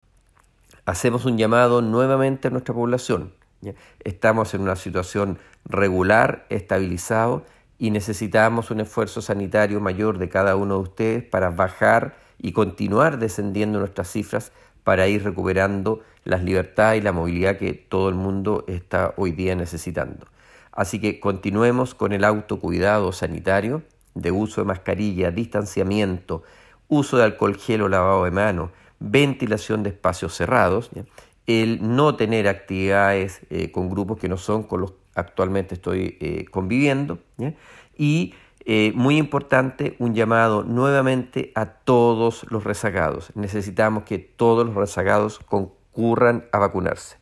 El Seremi de Salud Alejandro Caroca, insistió en que si bien la zona se encuentra en una situación estabilizada se requiere de una mayor colaboración de la comunidad para continuar bajando los números.